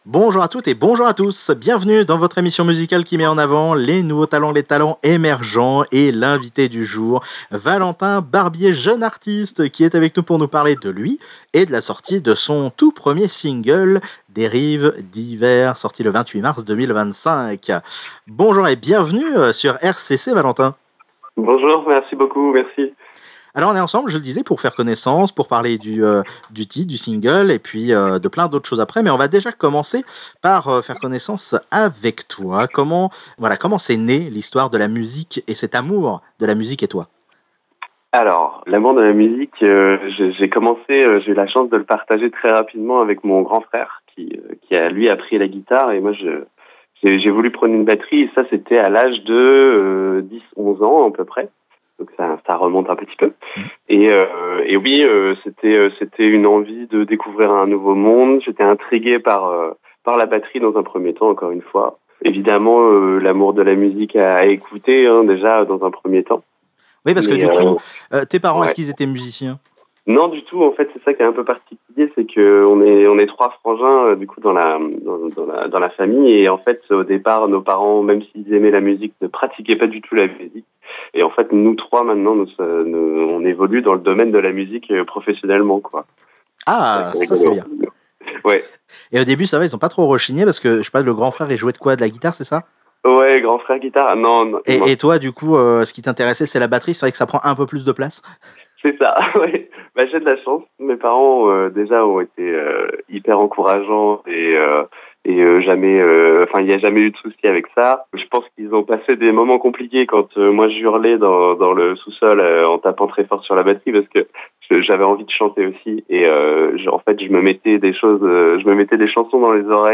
Au micro de RCC, il nous parle de lui, de son parcours, de sa participation a des concours, sa participation à des groupes et l’écriture de ses textes